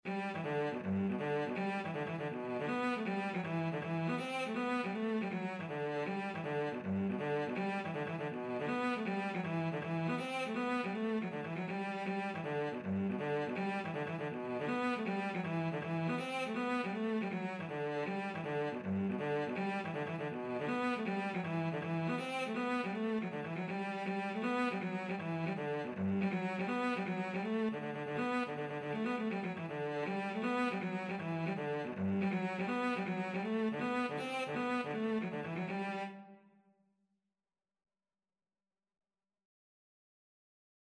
G major (Sounding Pitch) (View more G major Music for Cello )
2/4 (View more 2/4 Music)
Cello  (View more Intermediate Cello Music)
Traditional (View more Traditional Cello Music)